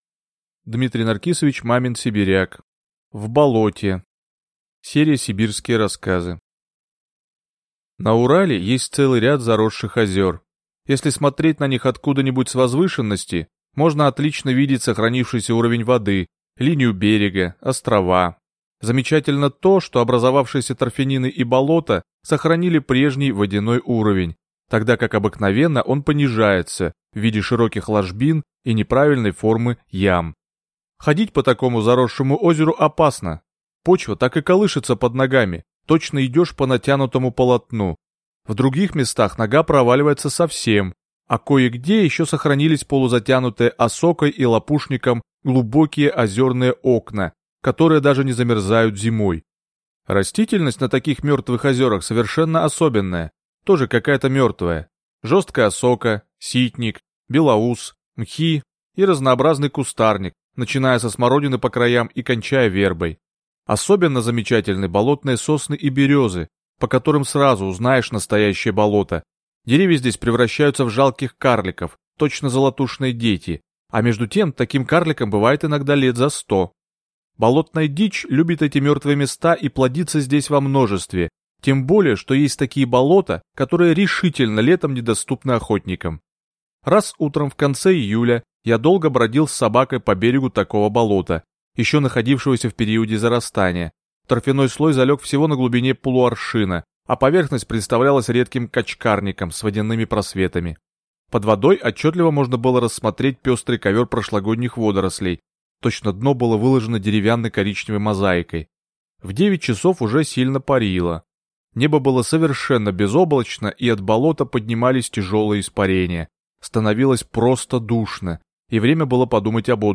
Аудиокнига В болоте | Библиотека аудиокниг